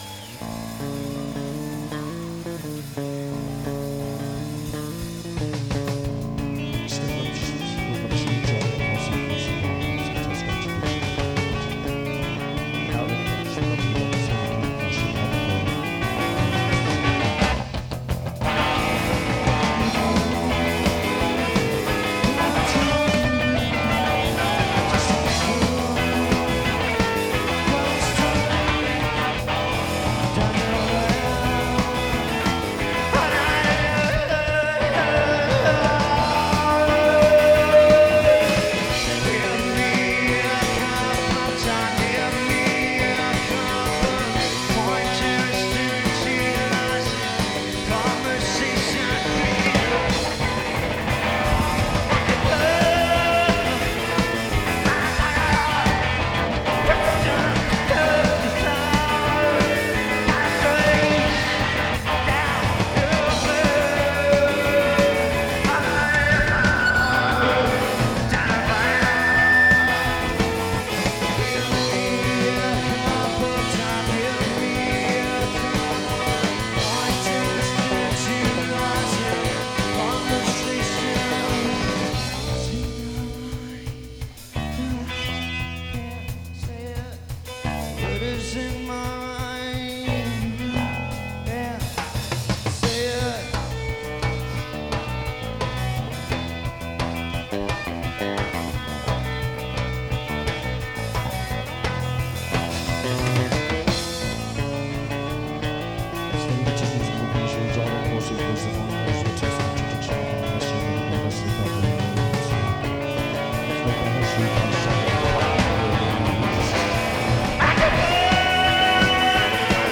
This review is on the soundboard show.